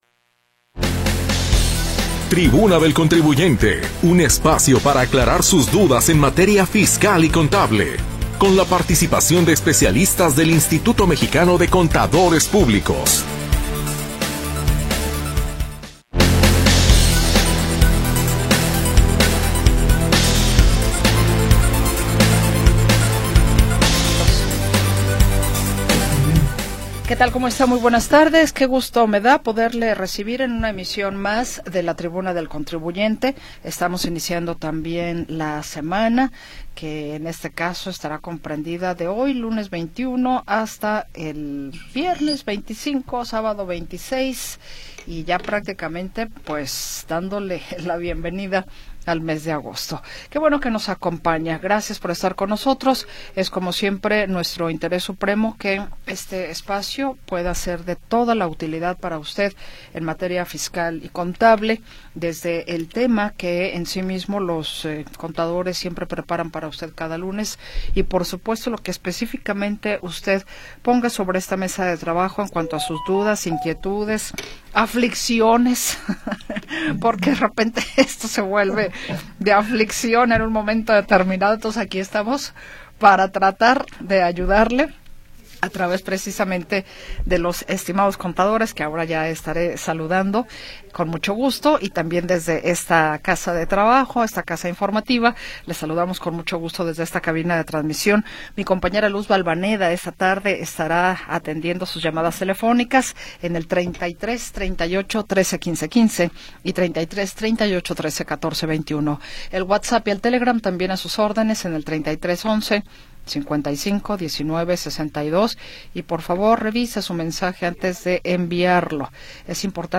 Orientación legal y contable con la participación de especialistas del Instituto Mexicano de Contadores.
Programa transmitido el 21 de Julio de 2025.